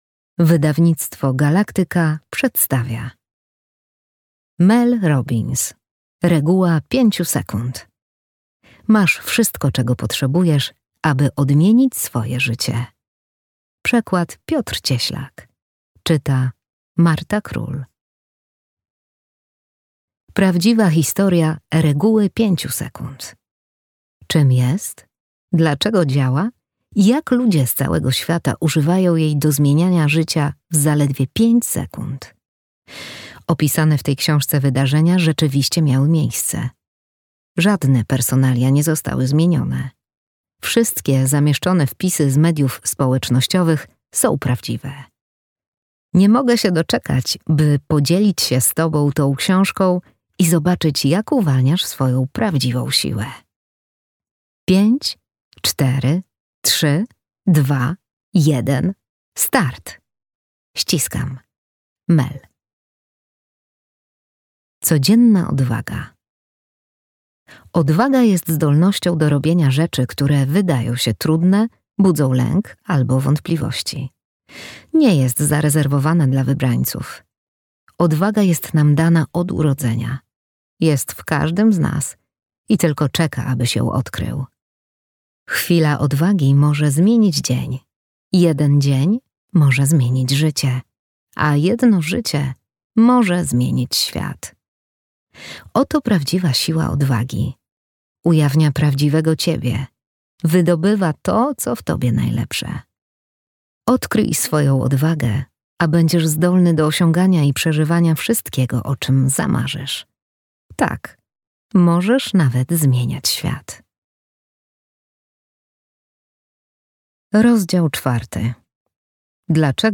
Audiobook Reguła 5 sekund.